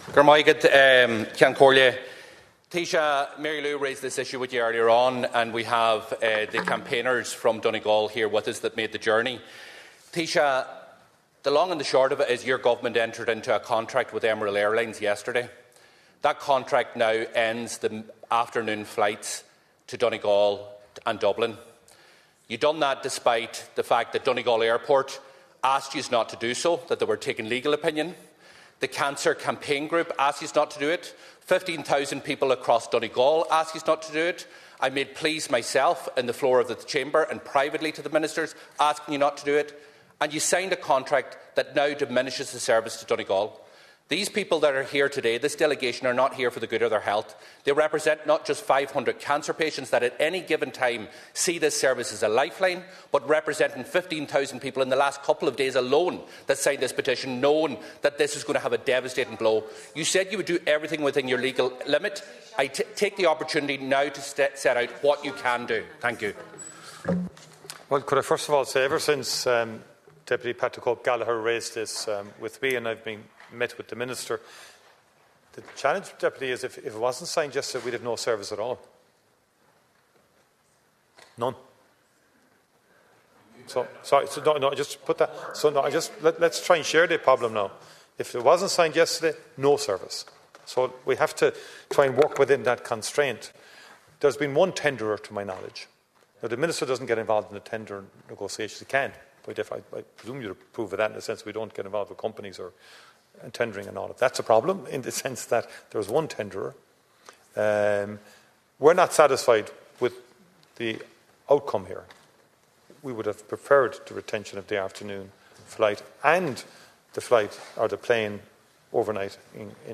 Micheál Martin was responding to criticism from Donegal Deputy Pearse Doherty, who said the agreed Public Service Obligation contract does not include the afternoon service, widely described as a lifeline for those travelling for medical appointments.
The Taoiseach said entering the contract was necessary to protect the route, adding that the Government is not satisfied with the reduction in service and will keep the matter under review: